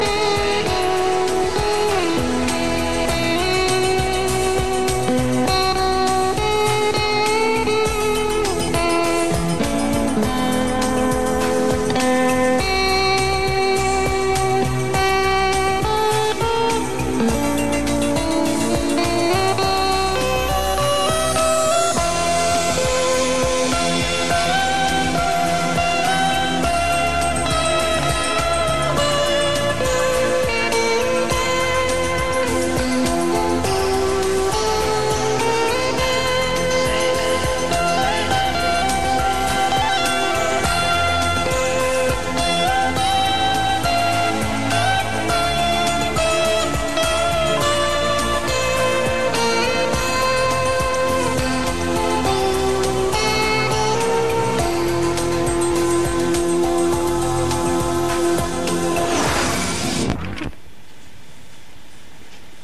Его гитару невозможно спутать с другой.